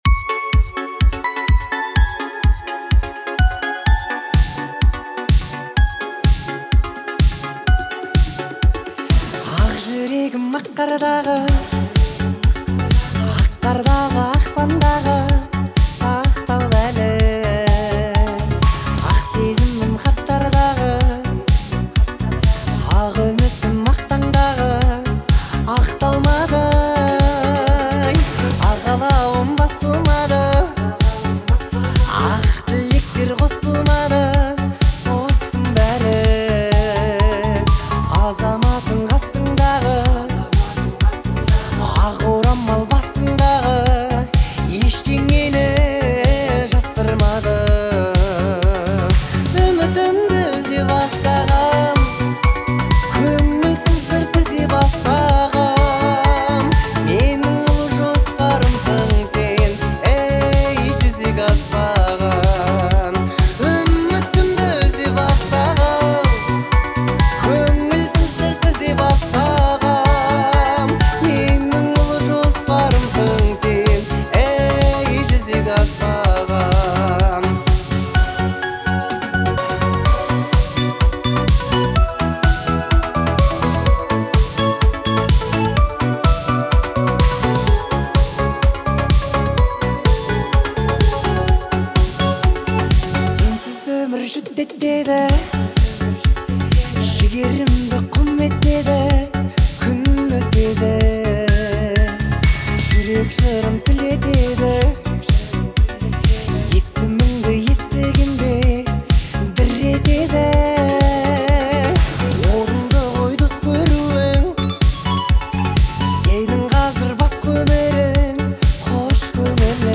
Казахские песни
Поп